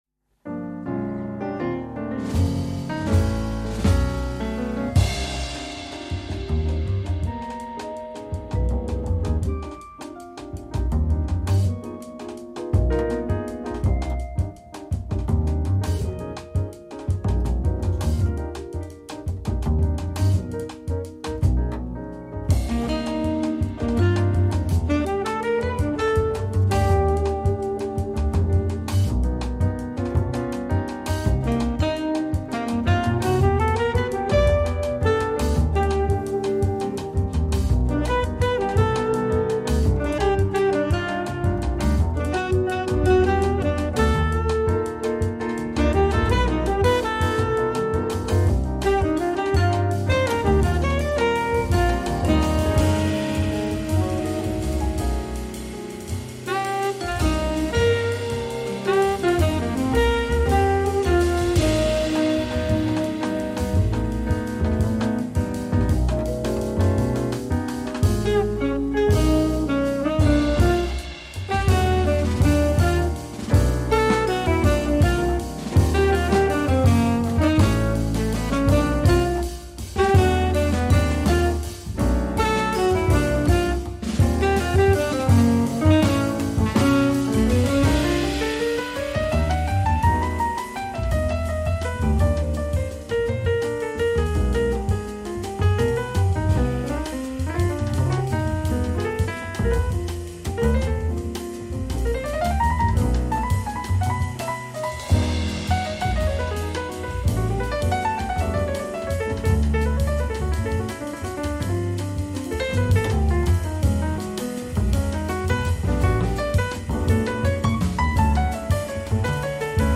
Nel bel mezzo di un ampio tour, la bassista fa un pit stop nei nostri studi